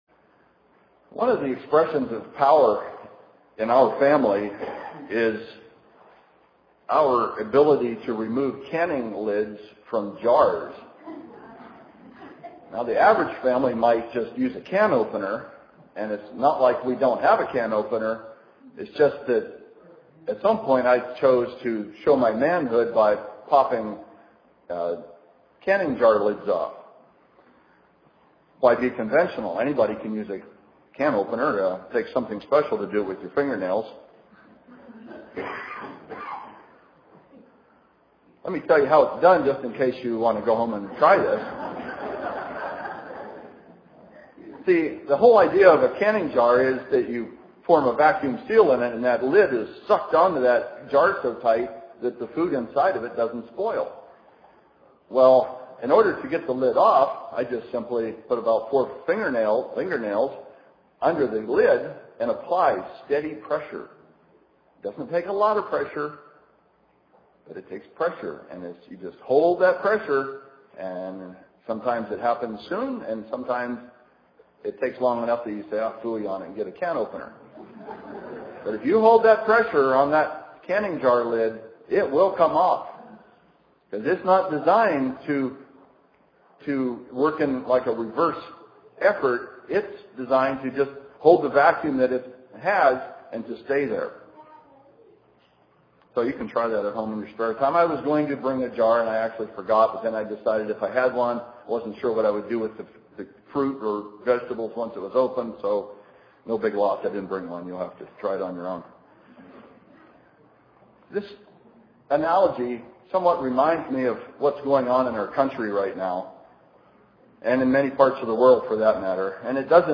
The law acts as a safety net, to keep us physically and spiritually safe. This society pressures us to set aside godly values. Sermon given on Last Day of Unleavened Bread.